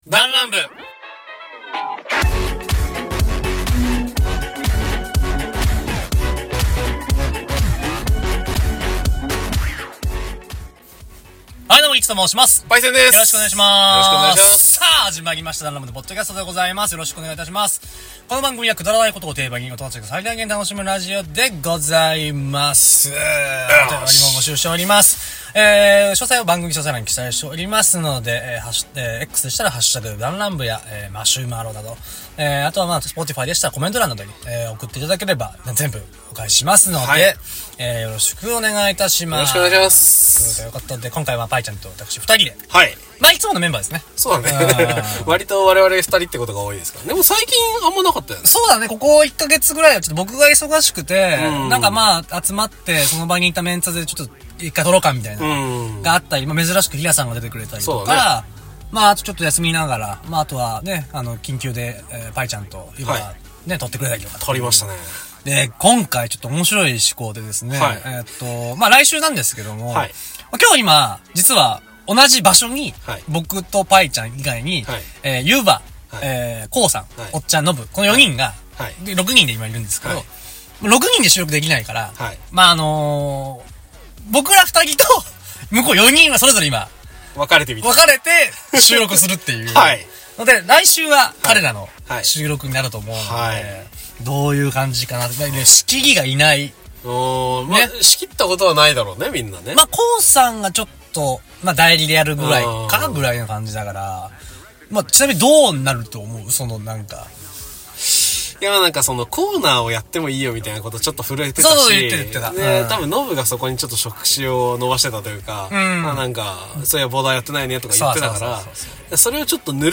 だんらん部 -アラサー男達の勝手なる雑談会-
楽しむことを妥協しない社会人 7人組 のお話。